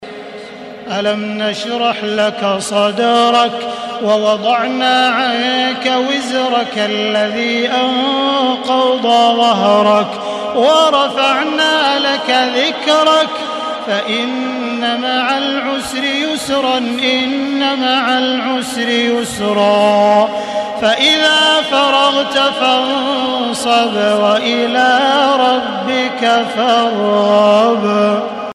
سورة الشرح MP3 بصوت تراويح الحرم المكي 1435 برواية حفص
مرتل